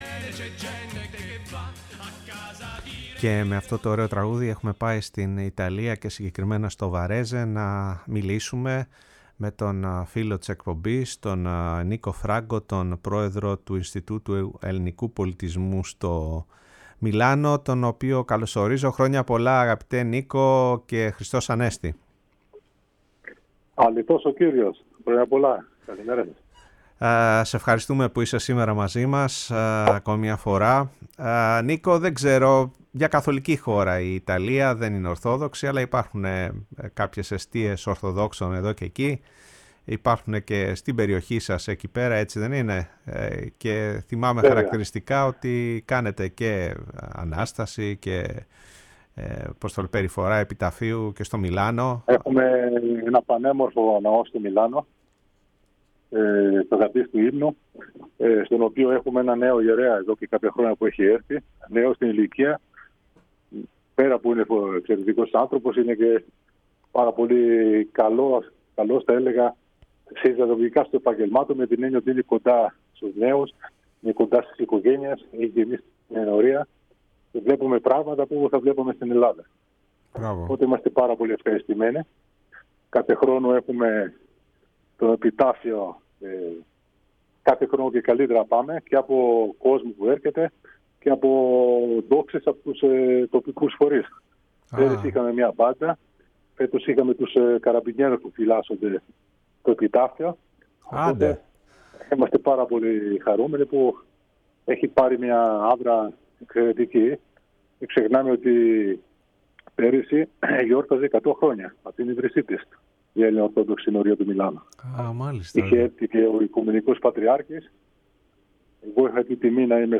Η ΦΩΝΗ ΤΗΣ ΕΛΛΑΔΑΣ Η Παγκοσμια Φωνη μας ΟΜΟΓΕΝΕΙΑ ΣΥΝΕΝΤΕΥΞΕΙΣ Συνεντεύξεις ακριβεια ΙΤΑΛΙΑ Μεγαλη Εβδομαδα